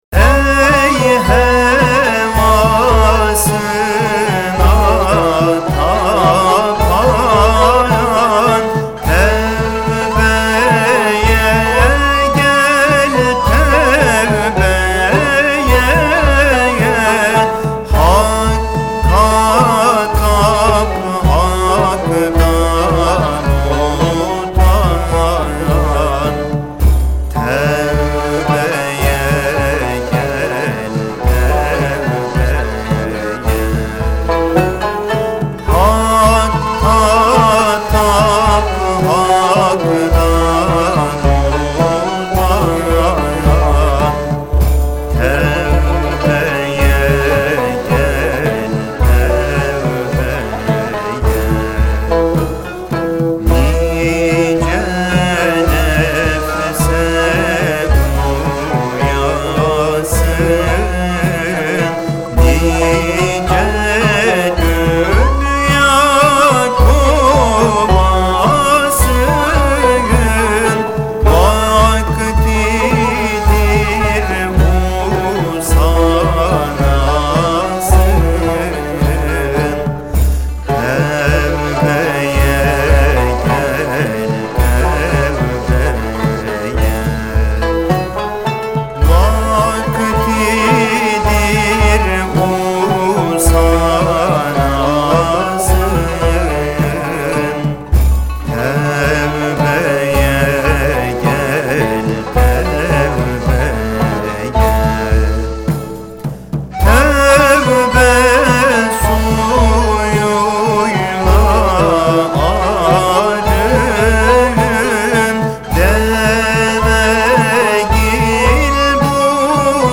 Tanbur Taksimi ve Kaside: